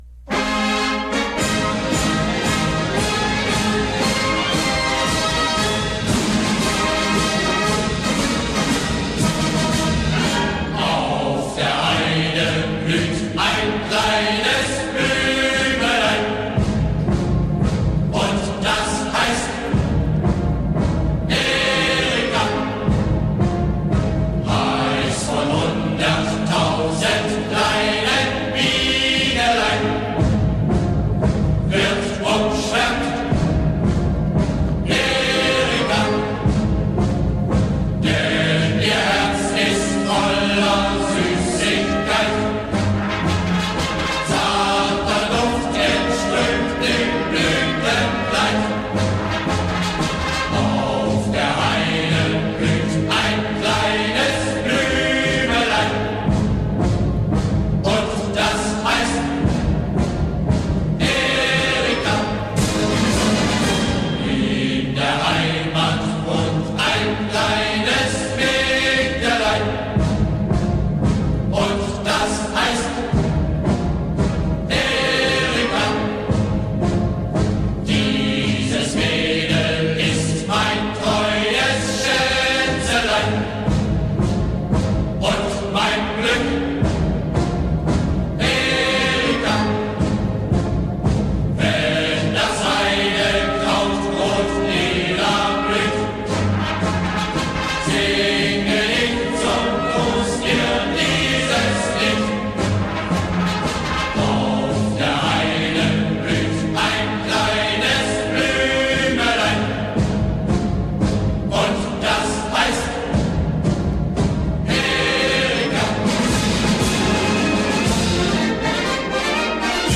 Erika_(Marching_Song_of_the_German_Military).mp3